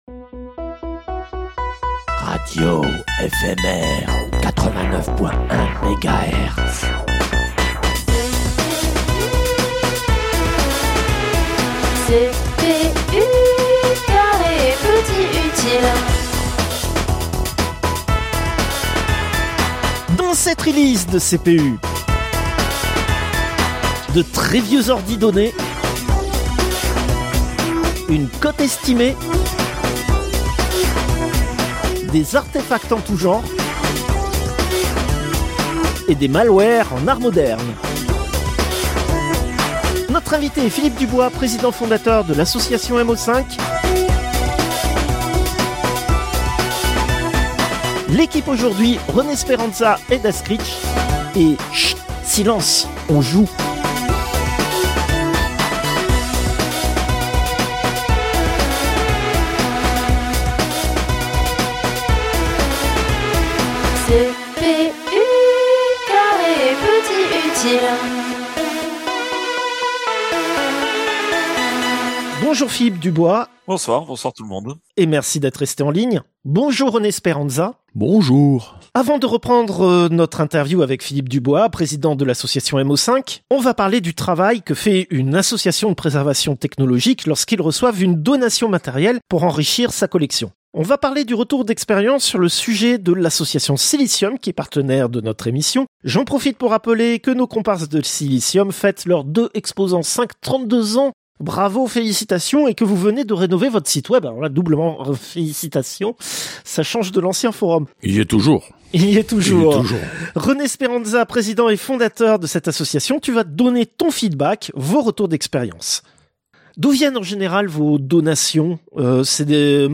Interview, quatrième partie